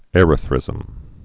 (ĕrə-thrĭzəm)